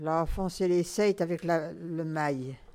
Localisation Sainte-Foy
Catégorie Locution